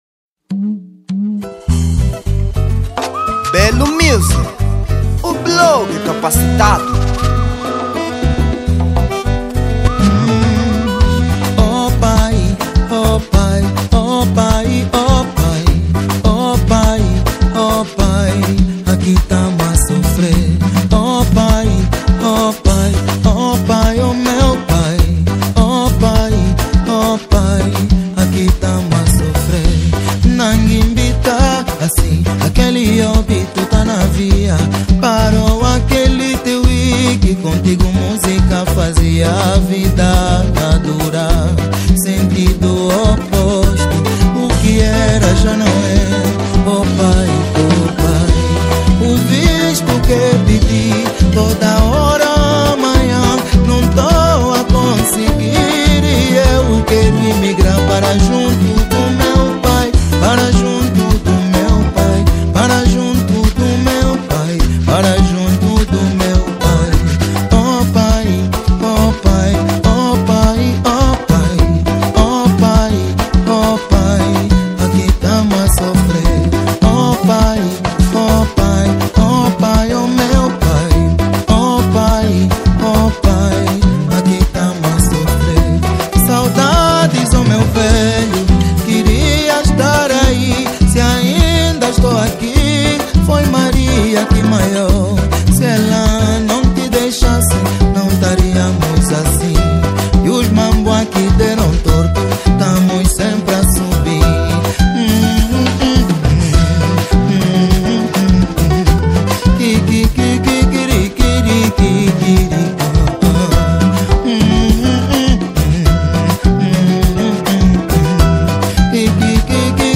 Género: Semba